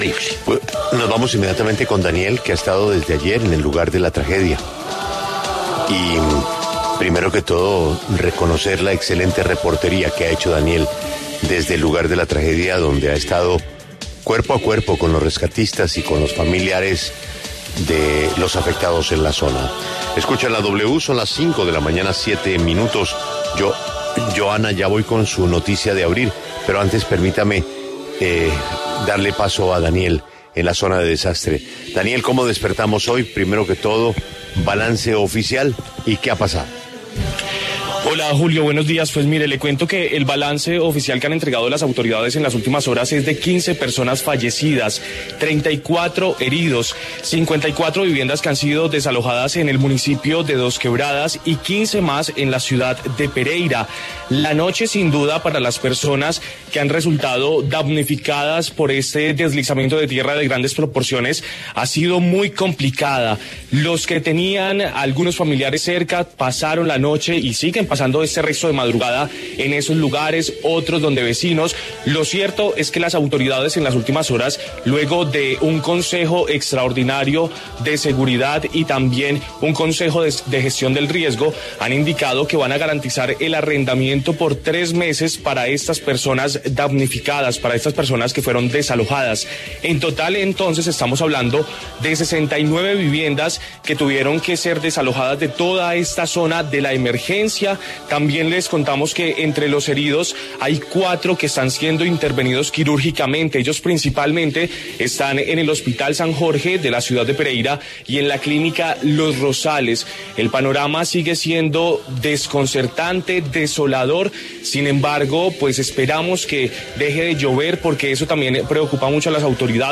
Reporte de la situación actual en Pereira